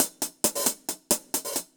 UHH_AcoustiHatB_135-04.wav